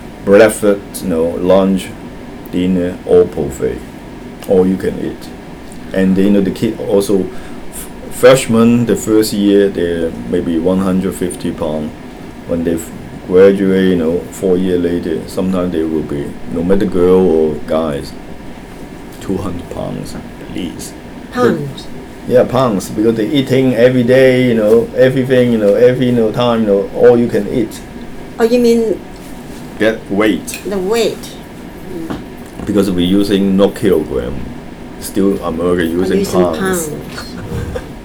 S1 = Taiwanese female S2 = Hong Kong male